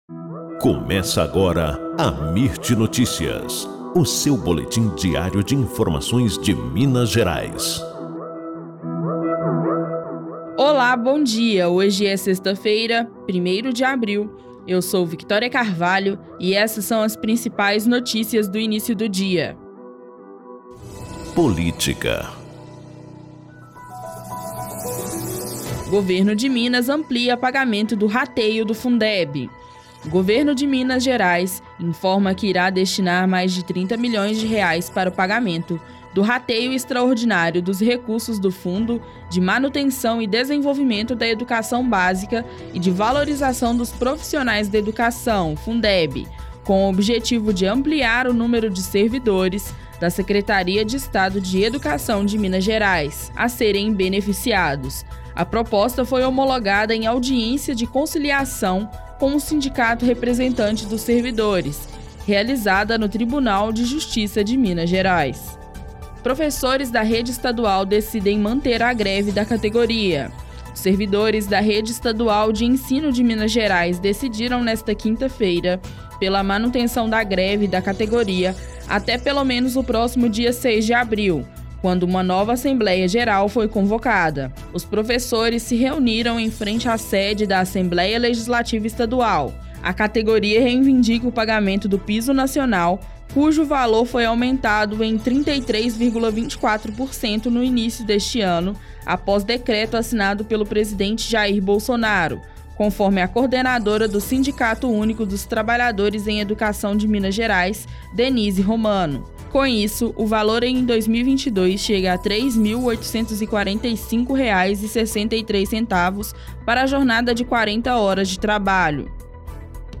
Boletim Amirt Notícias – 01 de abril